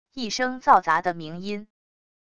一声噪杂的鸣音wav音频